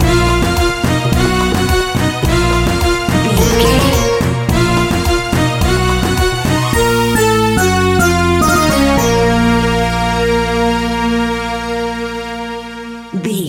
Aeolian/Minor
B♭
World Music
percussion